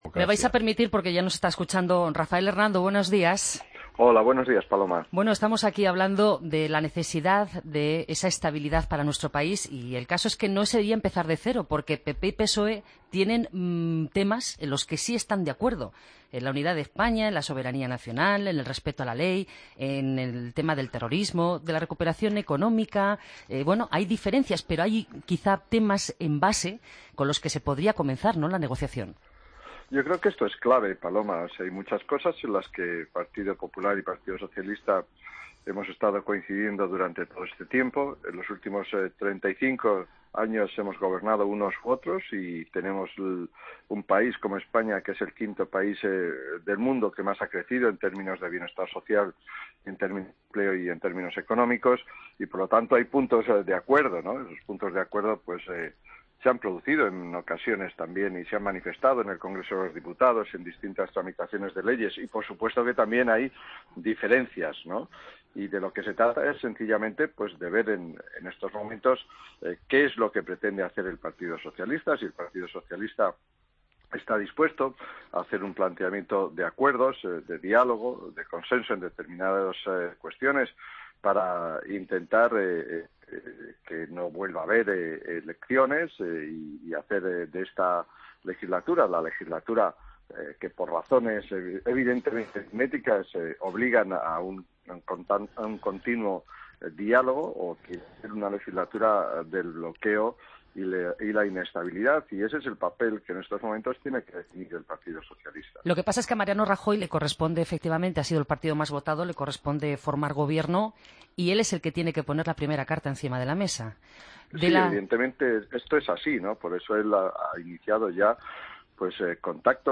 AUDIO: Escucha la entrevista a Rafael Hernando en 'Herrera en COPE'